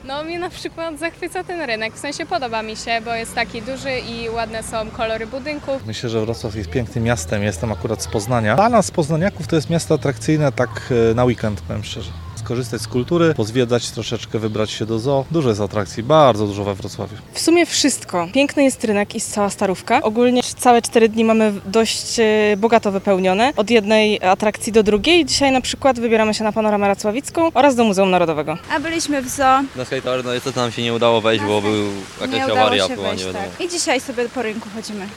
Wrocław pozostaje niezmiennie niezwykle atrakcyjnym celem podróży. Zapytaliśmy turystów, co najbardziej podoba im się w naszym mieście oraz co udało im się zobaczyć.